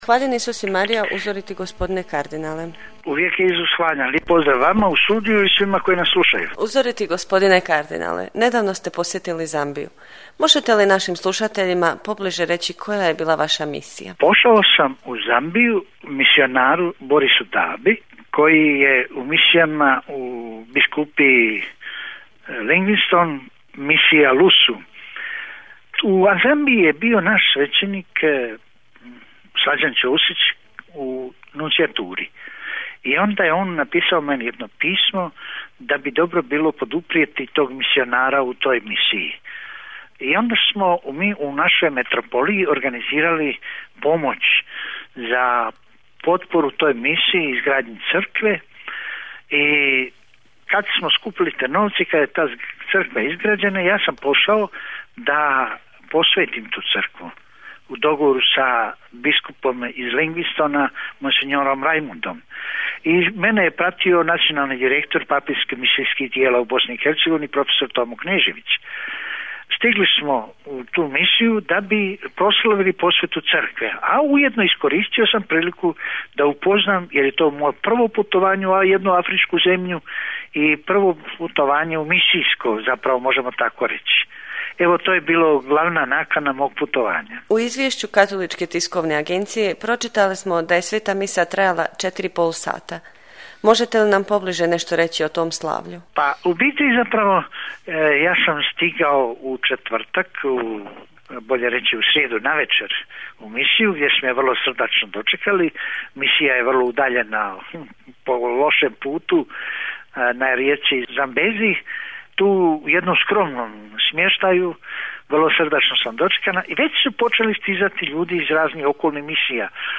Audio: Razgovor s kardinalom Vinkom Puljićem